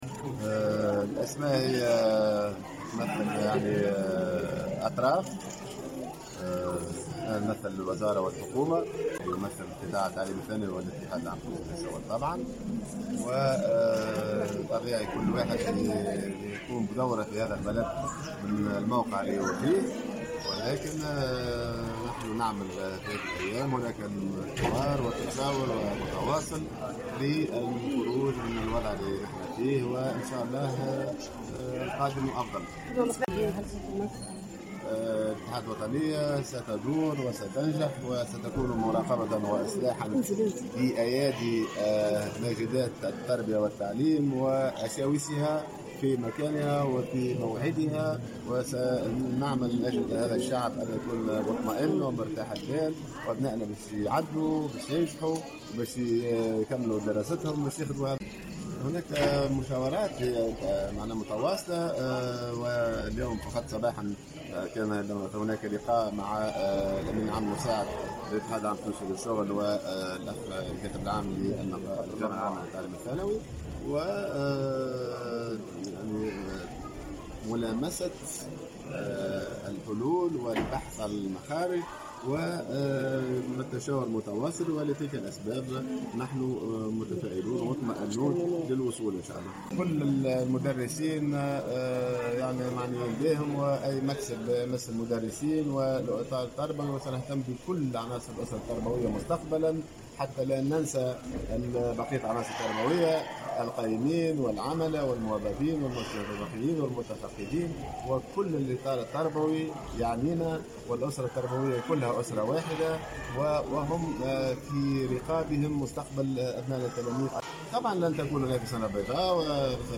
Dans une déclaration faite, ce vendredi, au correspondant de Tunisie Numérique, Boughdiri a ajouté que la situation se dirige vers un accord avec la partie syndicale, et que le ministère étudie des solutions pour les revendications sociales, mais à la limite acceptables.